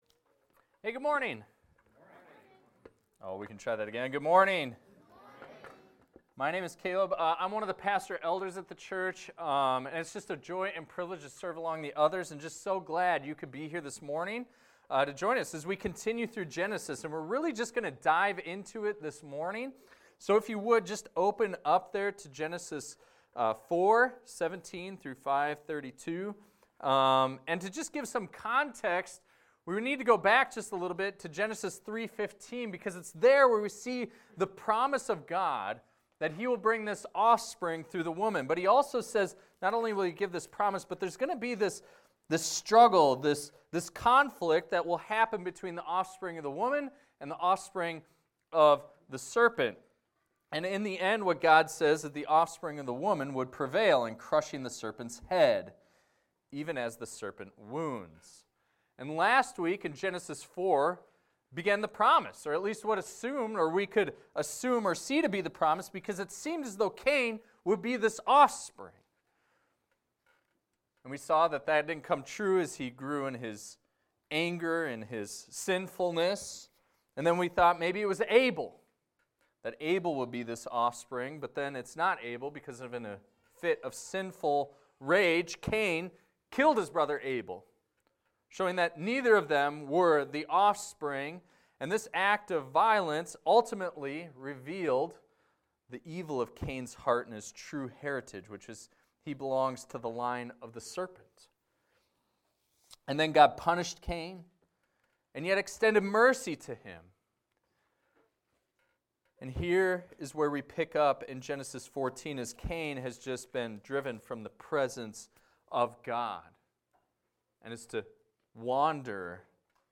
This is a recording of a sermon titled, "Darkest Before the Dawn."